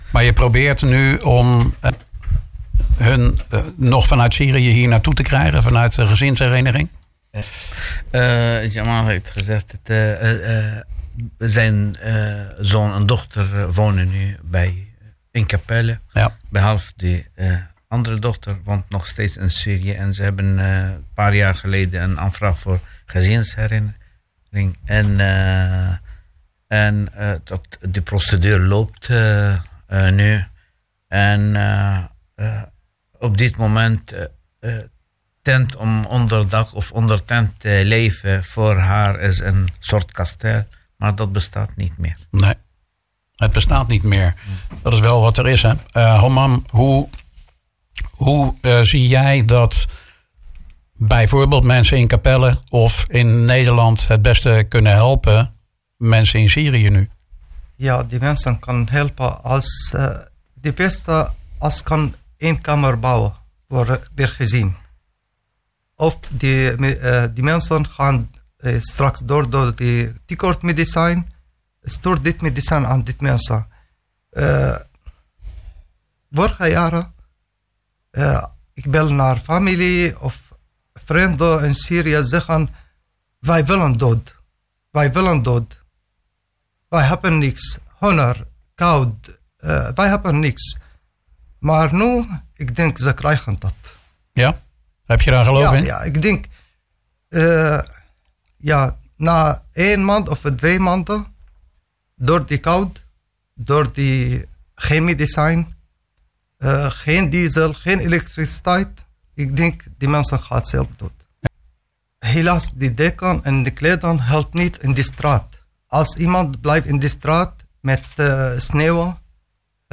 Dit is deel 2 van het gesprek.